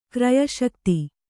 ♪ kraya śakti